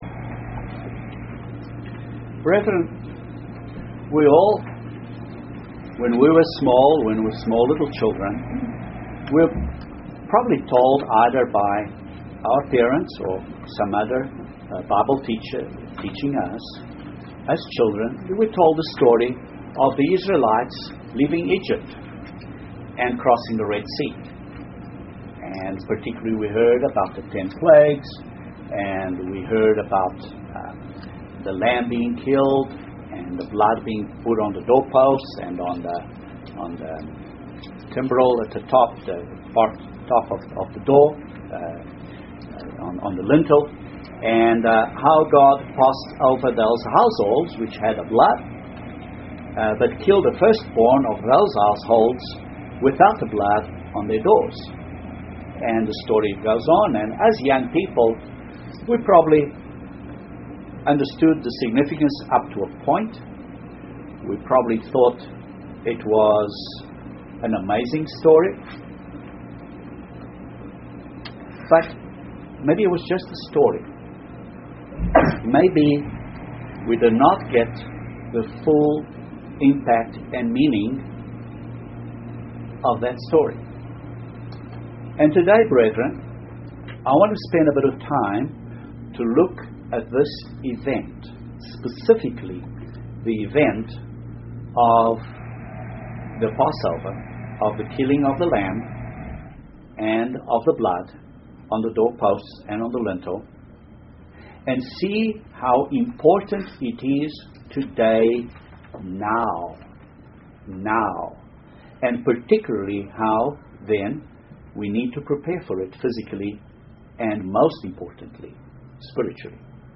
This sermon looks at that event, by first noting the date it was observed, and how Jesus Christ observed it on the right day and at the right time.